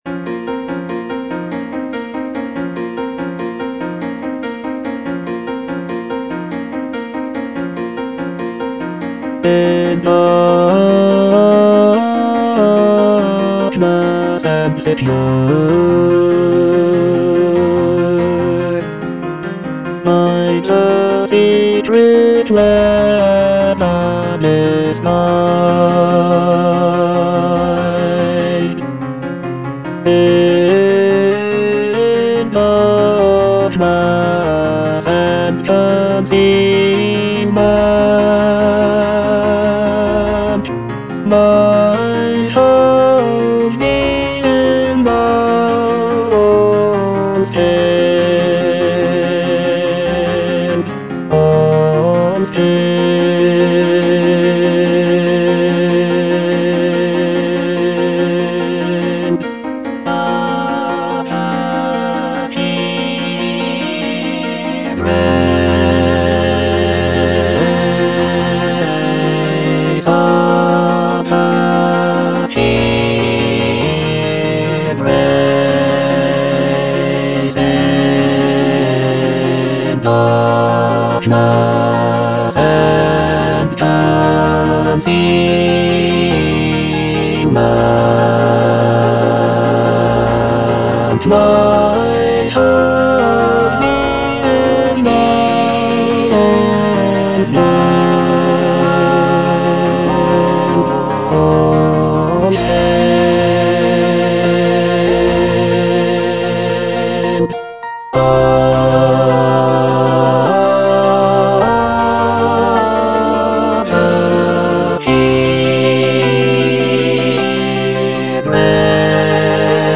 (bass I) (Emphasised voice and other voices) Ads stop
a choral work